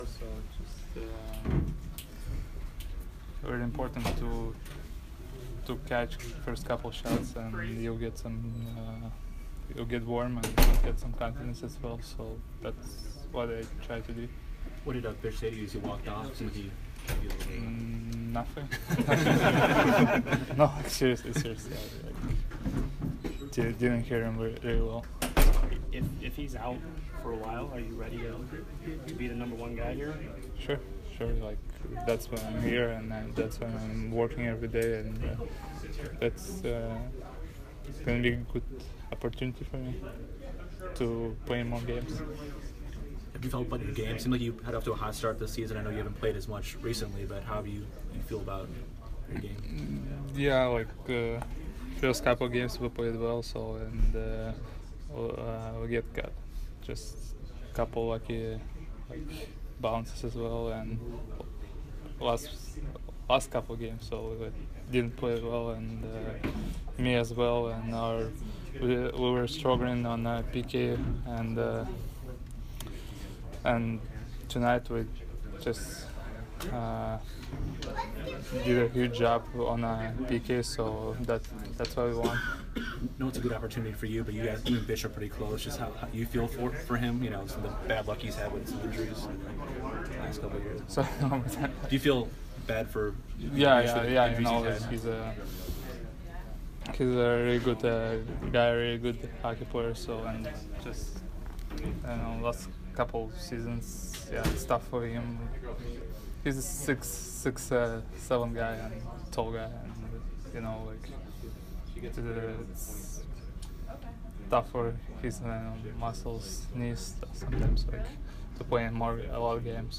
Vasilevskiy Post Game 12/20